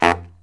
LOHITSAX10-R.wav